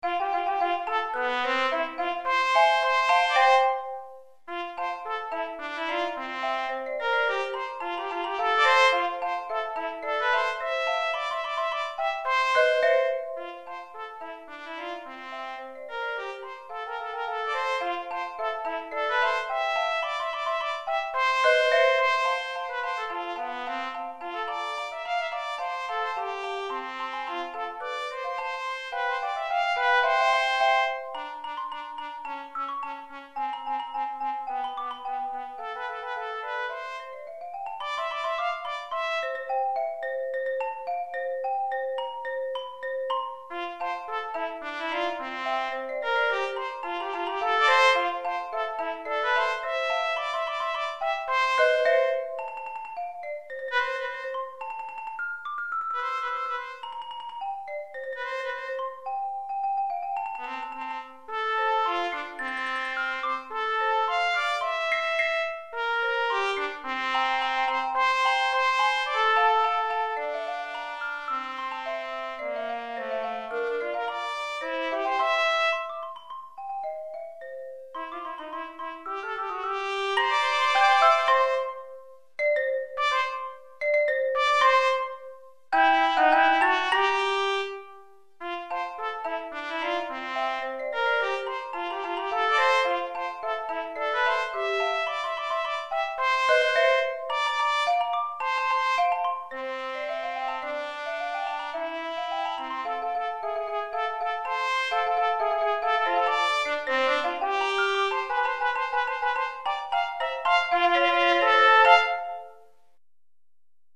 DUO pour cornet sib (ou trompette) et xylophone